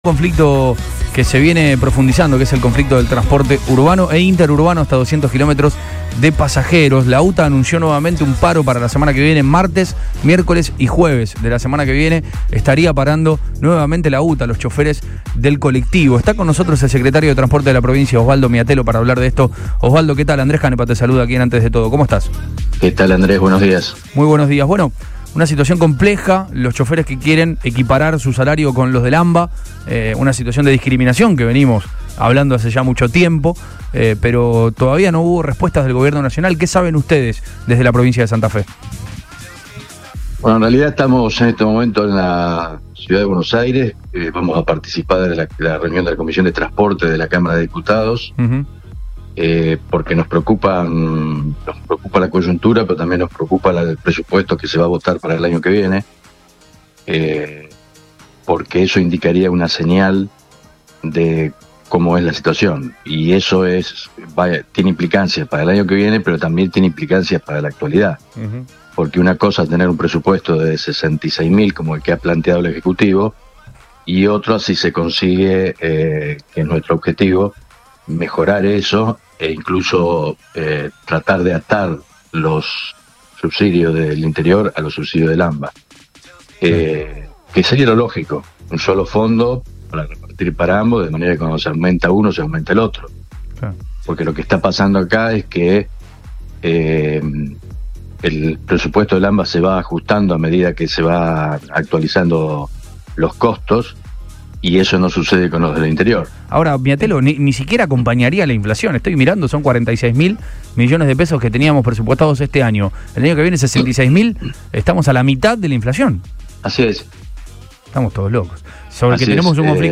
EN RADIO BOING
Osvaldo Miatello, secretario de Transporte de Santa Fe